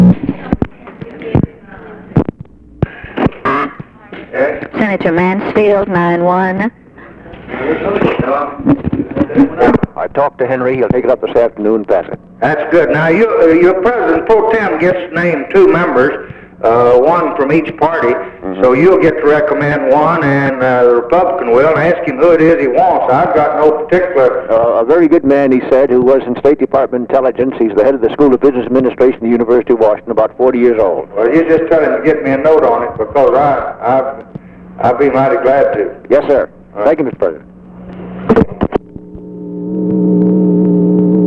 Conversation with MIKE MANSFIELD, February 18, 1964
Secret White House Tapes